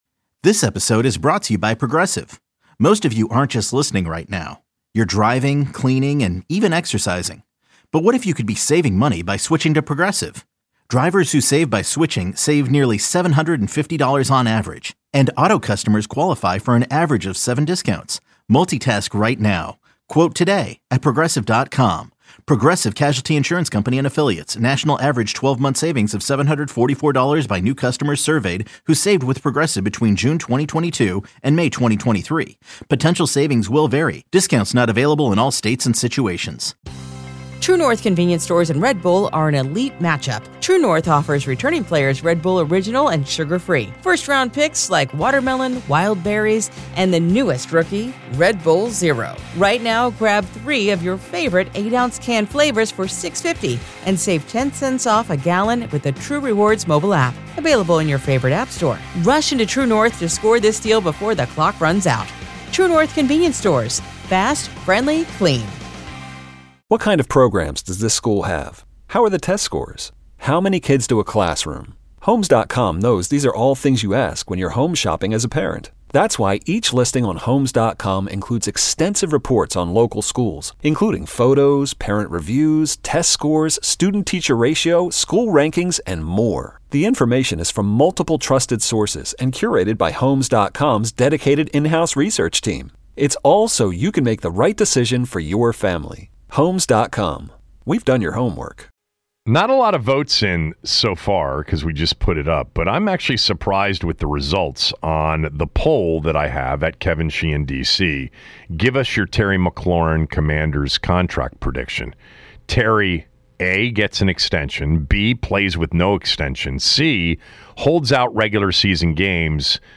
More calls on what fans think will happen with this Terry McLaurin contract negotiation saga.